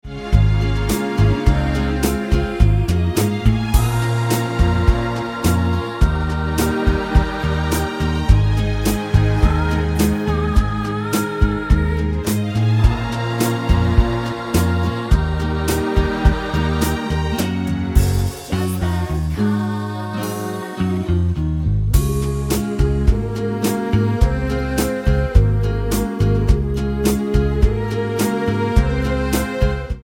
Tonart:C# mit Chor
Die besten Playbacks Instrumentals und Karaoke Versionen .